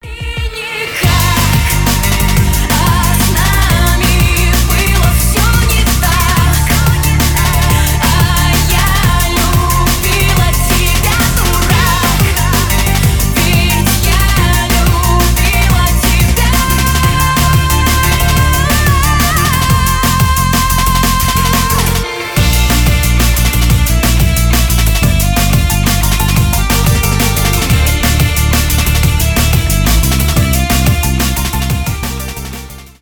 ремиксы , драм энд бейс , дабстеп
поп